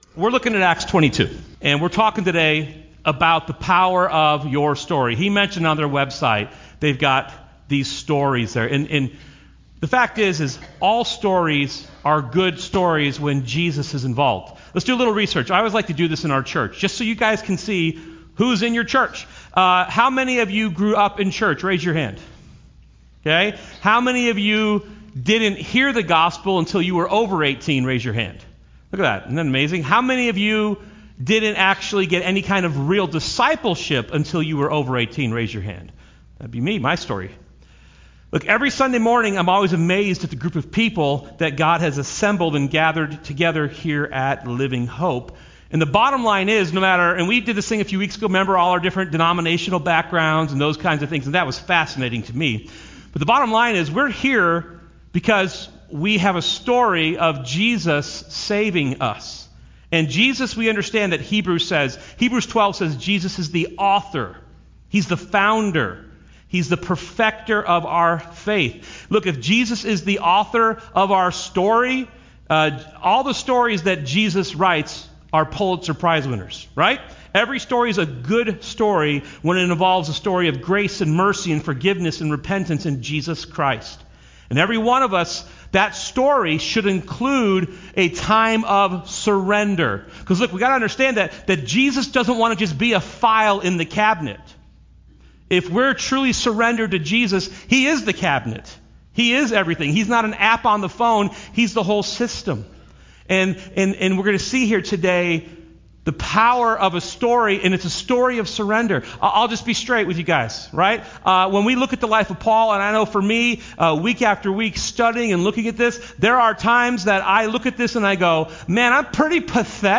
Related Topics: sermon